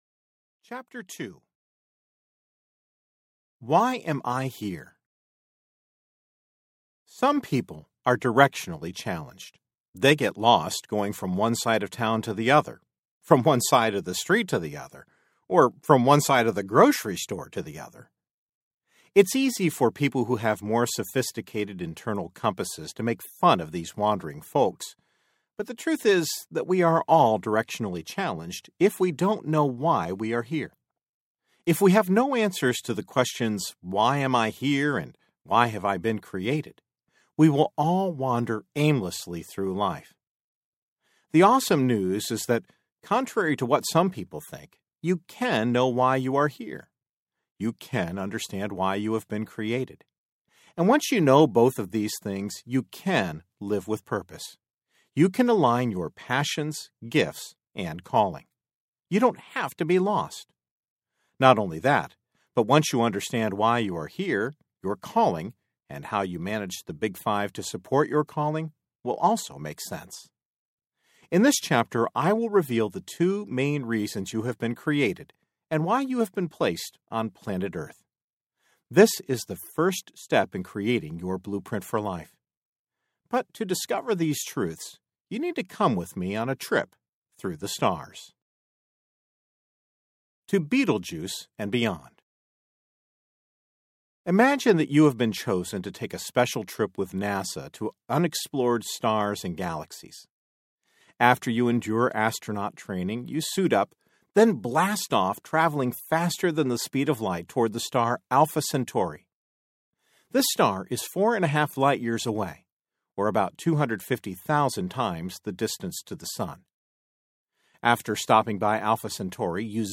Your Blueprint for Life Audiobook
Narrator
5.7 Hrs. – Unabridged